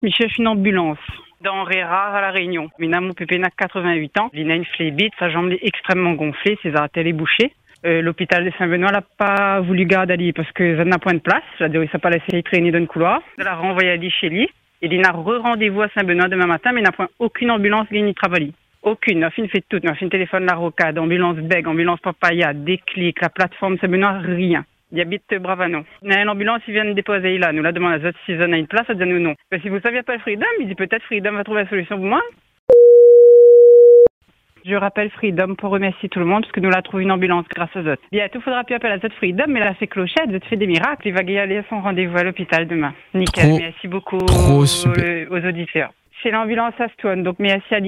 À écouter : le témoignage de cette jeune fille